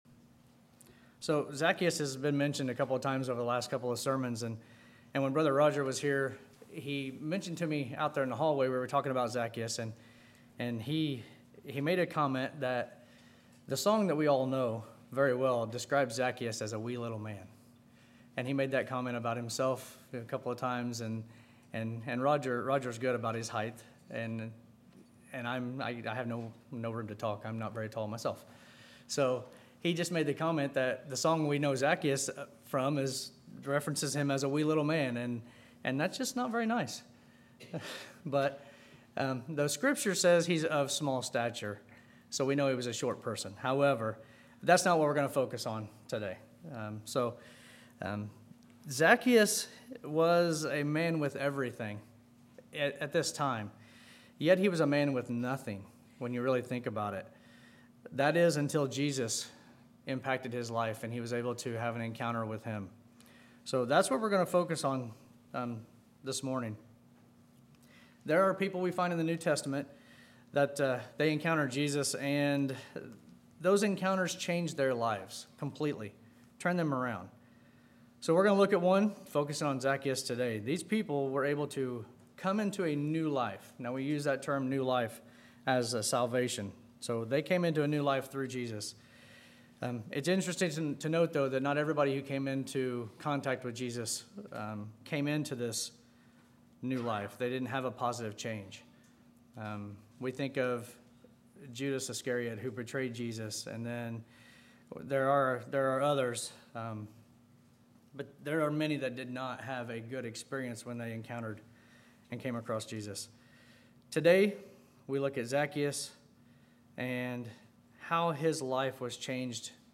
Sermons
Service: Sunday AM